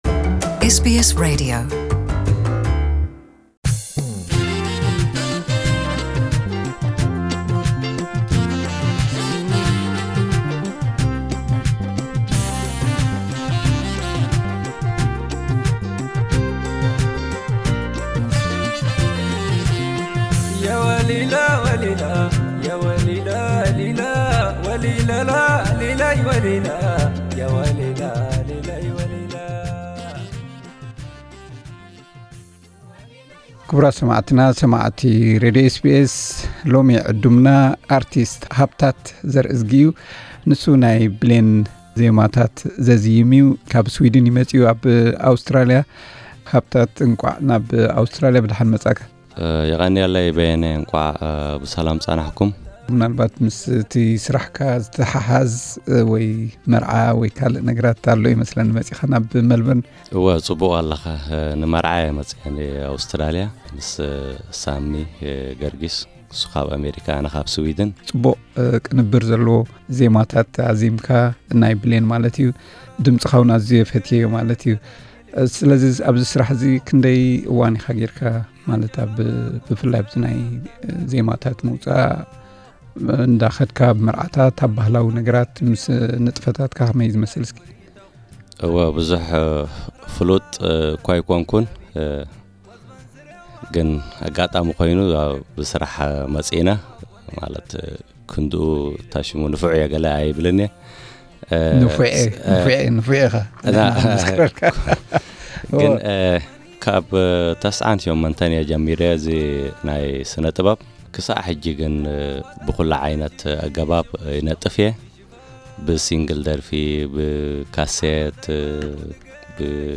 ዕላል ኤስቢኤስ ትግርኛ ምስ ስነጥበባውያን